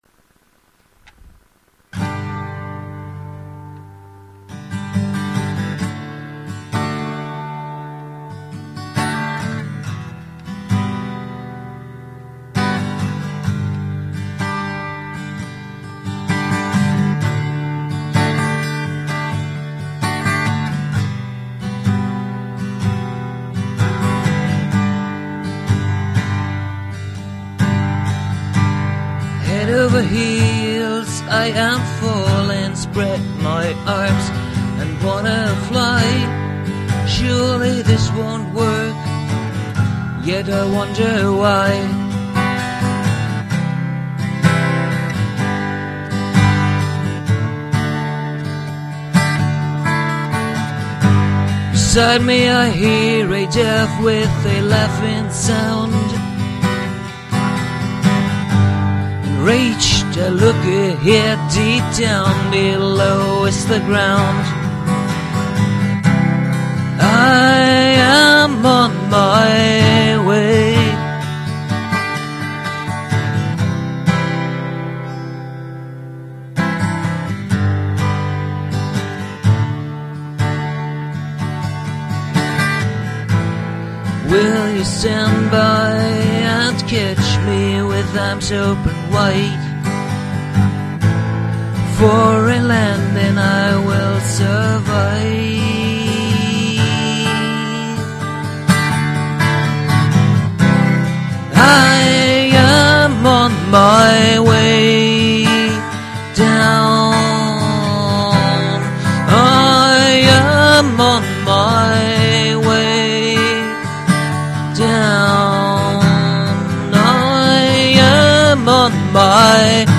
TypEP (Studio Recording)
Acoustic Jam Version